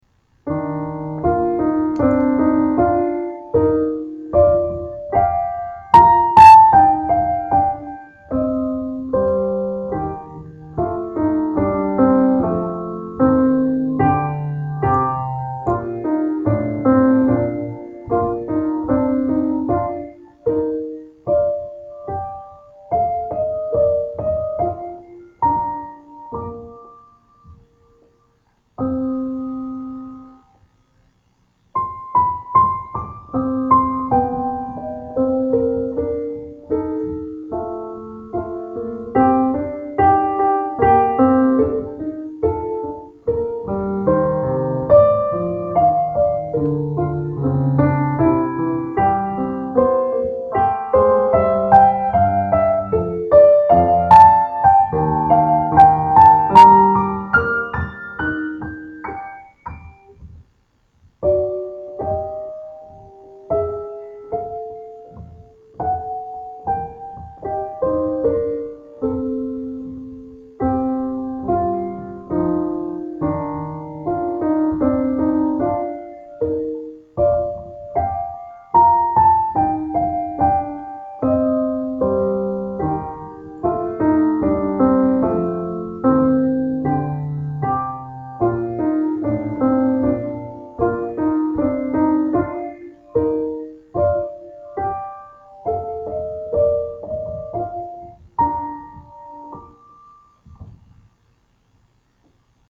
Waltzes